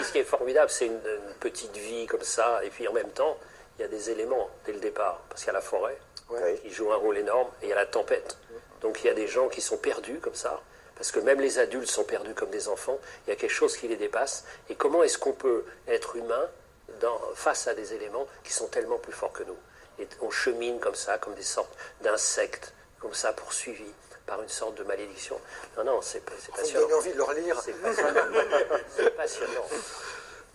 Voici comment en parlait Pierre Lemaître à la télévision il y a quelque temps.